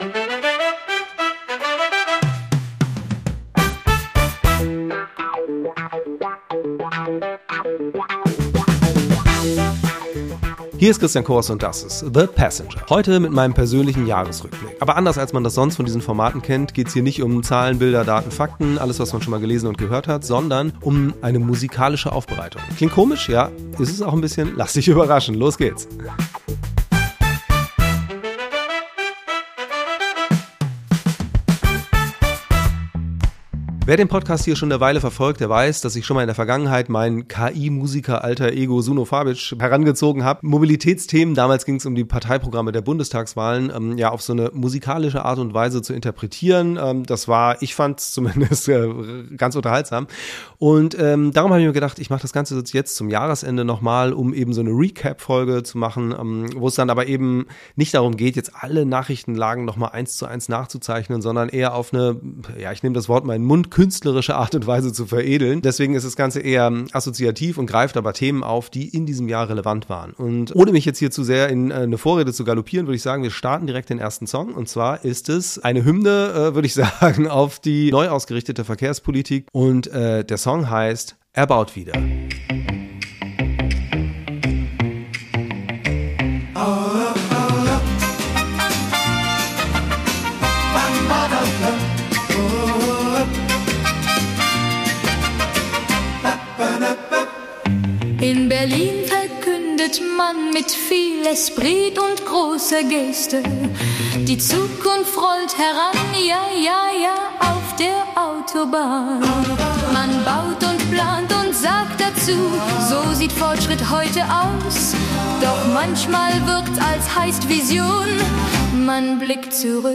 Entstanden sind fünf Lieder über marode Infrastruktur, neue Autobahnen, das Deutschlandticket und sie Digitalisierung sowie eine große Vision für die Bahn, interpretiert von der grandiosen und natürlich ebenfalls vollkommen virtuellen KI-Chanteuse Elodie Melody.